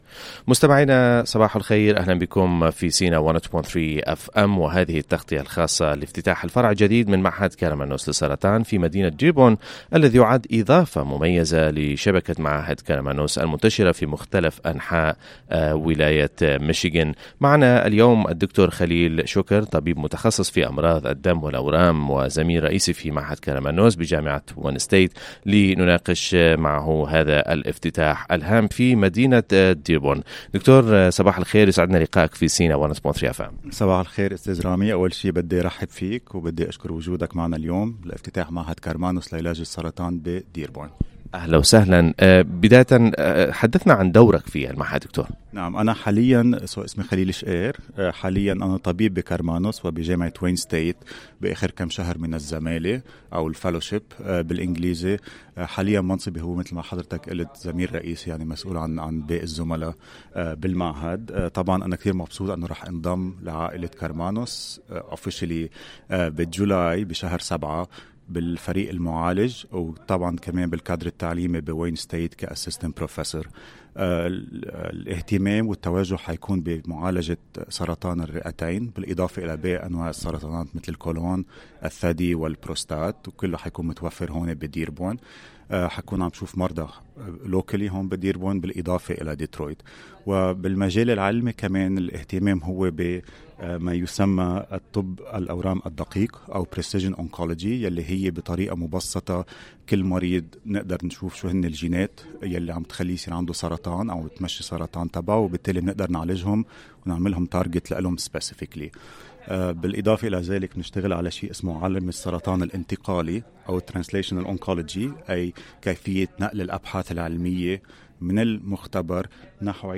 One interview segment is below.